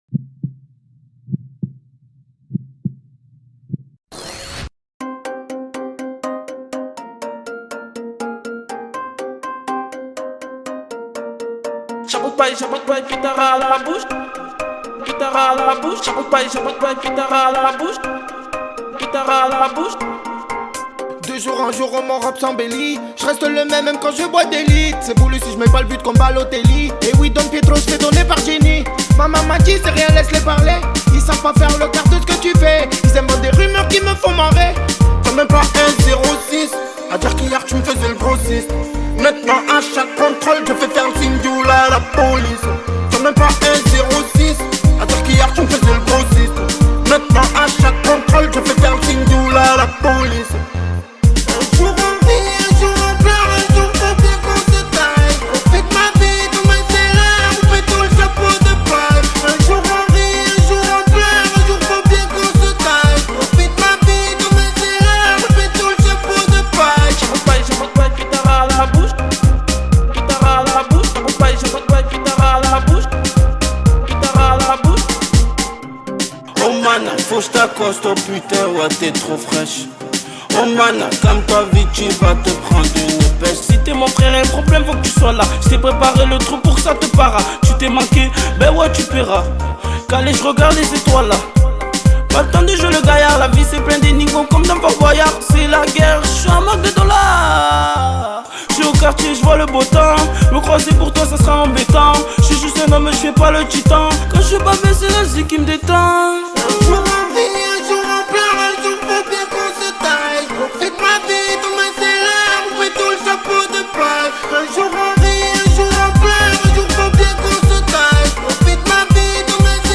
french rap, pop urbaine Écouter sur Spotify